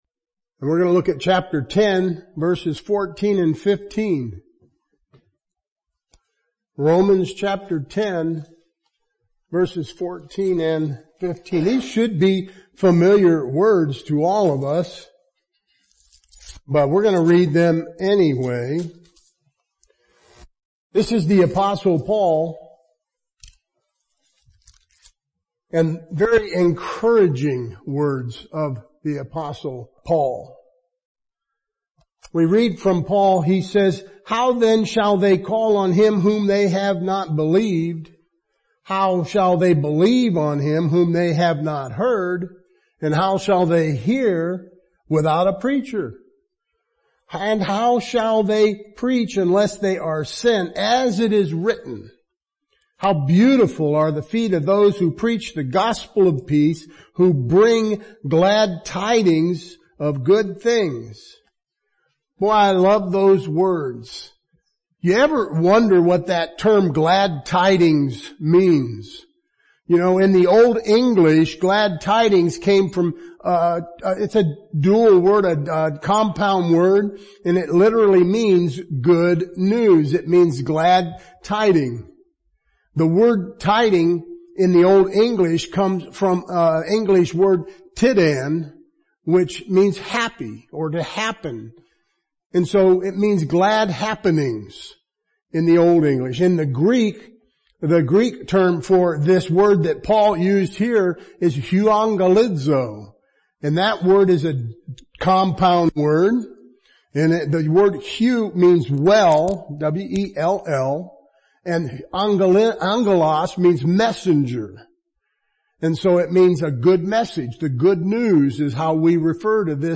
• Yet, tonight in this lesson I wanted to address the good news through some of the words of glad tidings that bring the church a sense of joy.